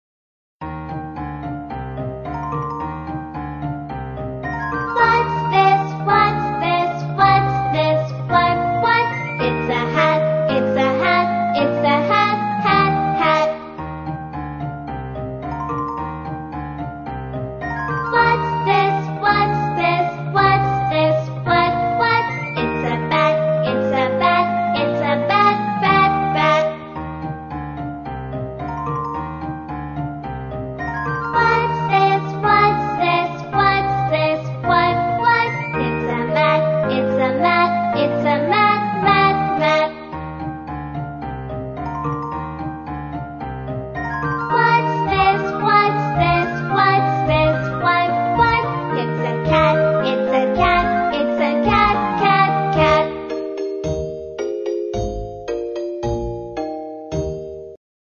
在线英语听力室英语儿歌274首 第252期:What's this的听力文件下载,收录了274首发音地道纯正，音乐节奏活泼动人的英文儿歌，从小培养对英语的爱好，为以后萌娃学习更多的英语知识，打下坚实的基础。